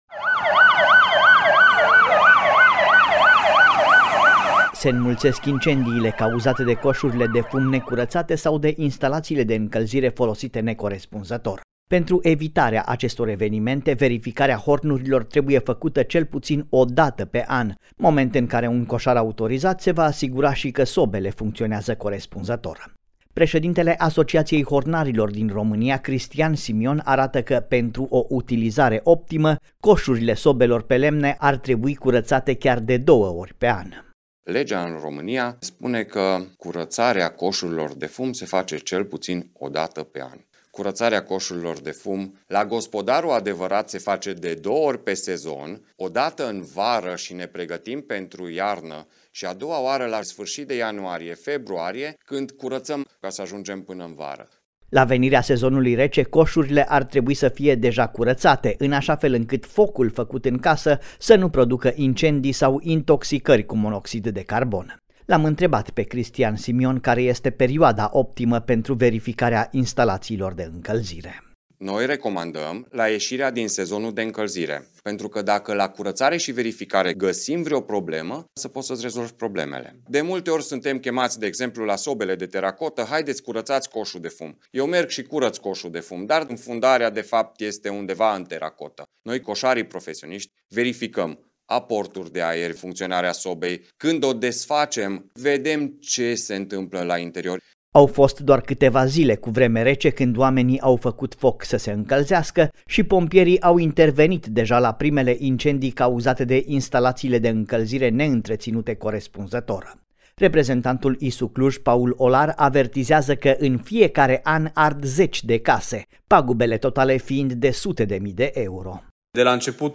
reportaj-curatare-hornuri.mp3